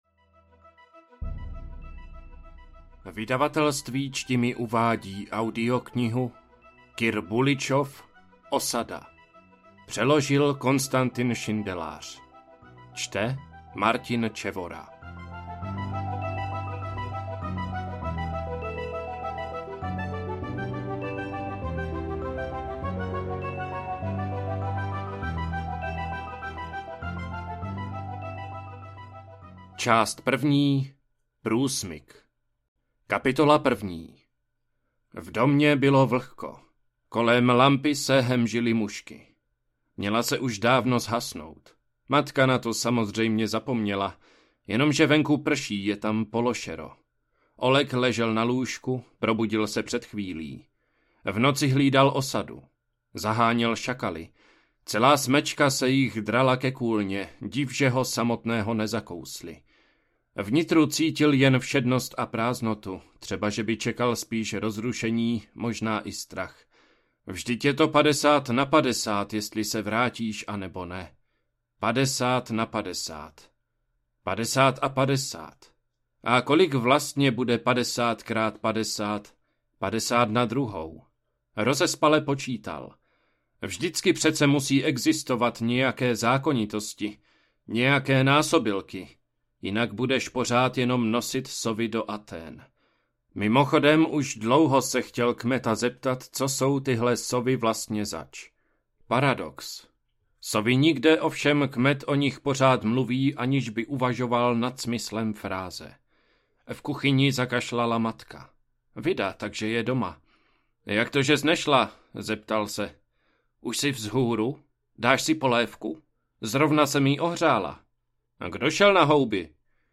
Osada audiokniha
Ukázka z knihy